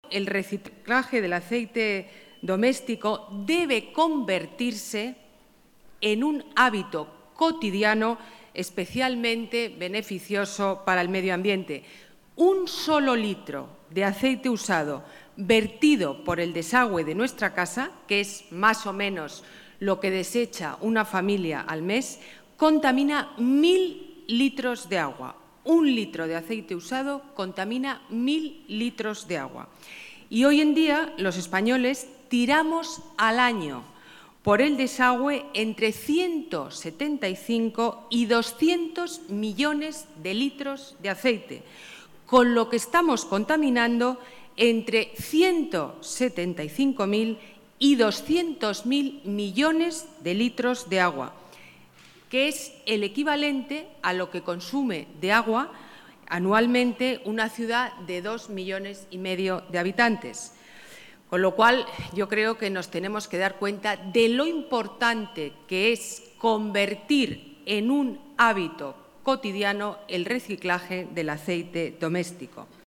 Nueva ventana:Declaraciones de la delegada de Medio Ambiente, Ana Botella